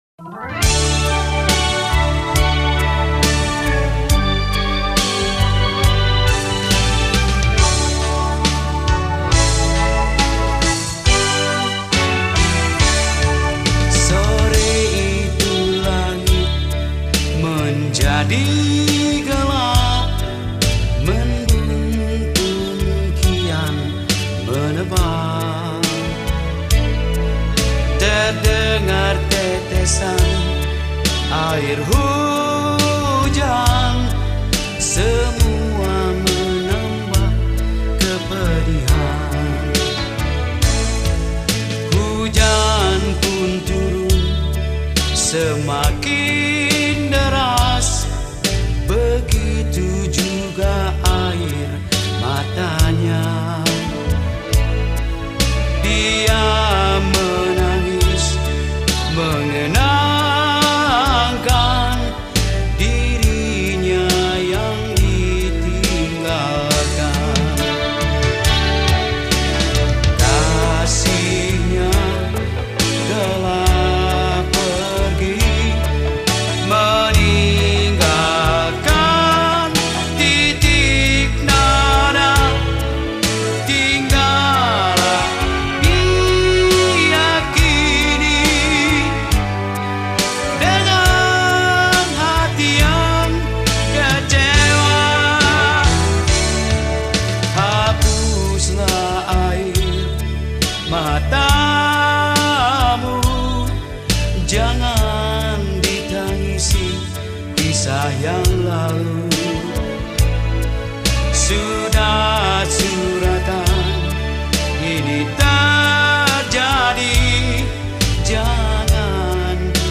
Nusantara Song , Skor Angklung